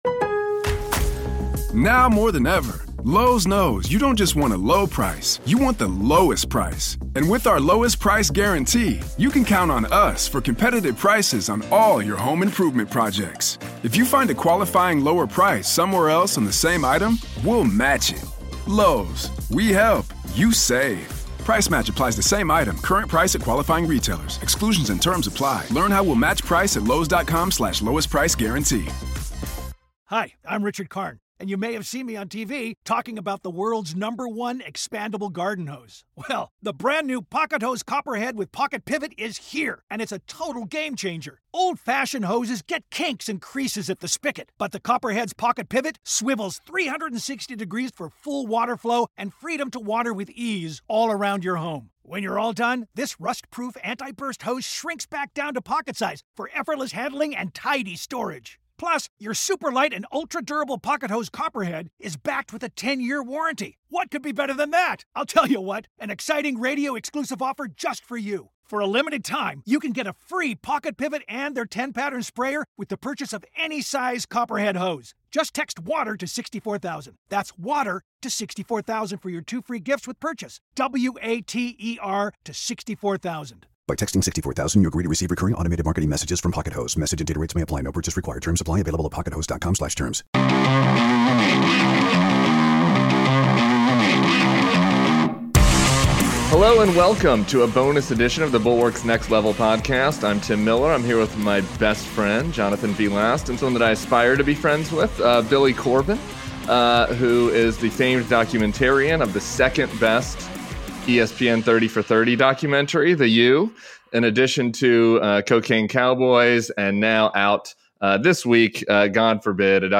Politics, News, News Commentary